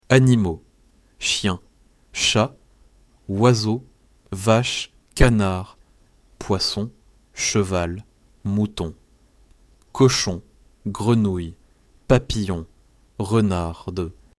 Lesson 6